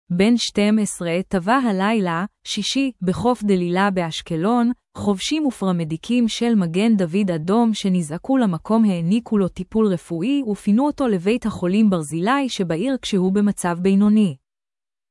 🔤 Как читается:
ben 12 ta-va ha-li-lah (shi-shi) ba-hof da-li-lah ba-ash-ke-lon, kho-va-shim ve-pa-ra-me-di-kim shel ma-gen da-vid a-dom she-ne-za-ku la-ma-kom ha-a-ni-ku lo ti-pul re-fu-i ve-fi-nu o-to la-veit ha-kho-lim ba-ra-zi-li she-va-ir ka-she-hu ba-ma-tsav be-y-no-ni.